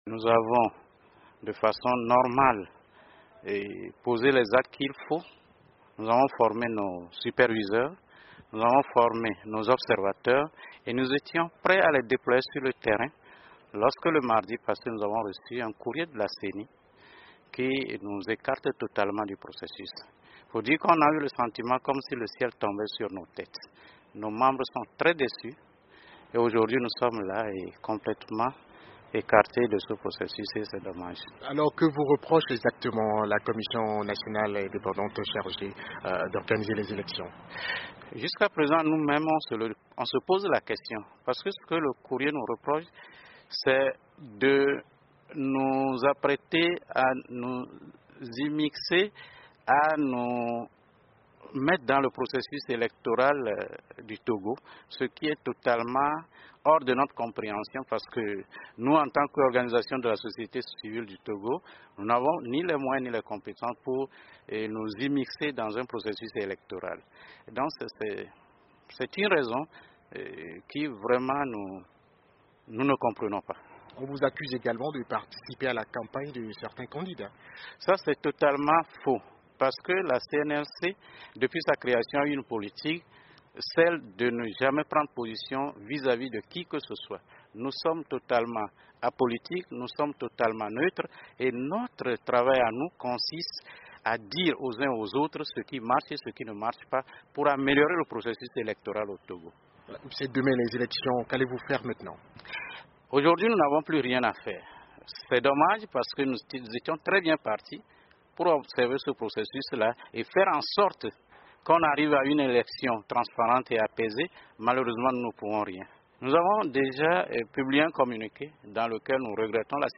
Entretien avec le président de l'une des ONG suspendues par la commission électorale